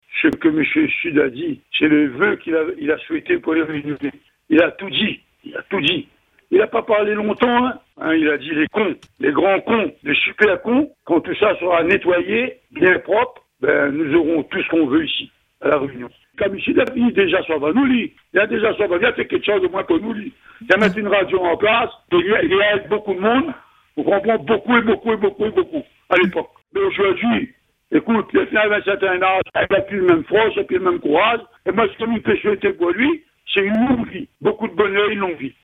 En ce 31 décembre 2025, Radio Free Dom était aux côtés de ses auditeurs pour célébrer l’arrivée de 2026.